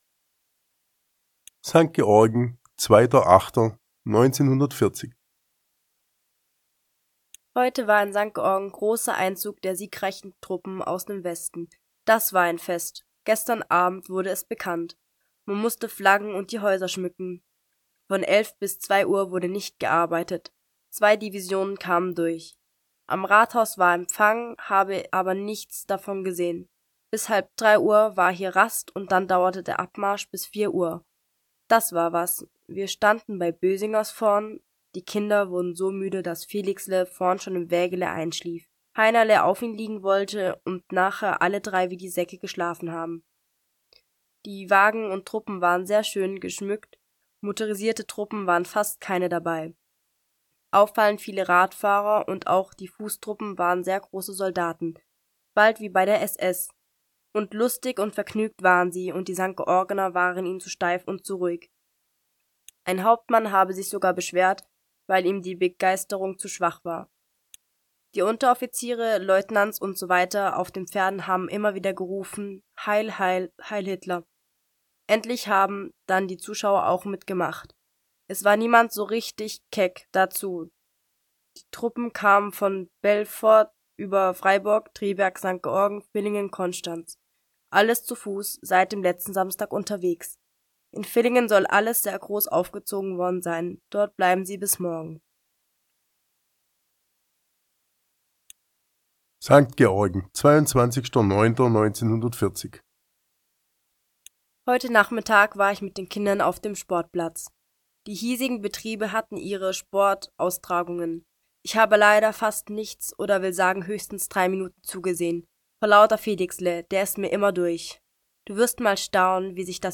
Das folgende Hörbeispiel gibt Auszüge aus diesem Briefwechsel wieder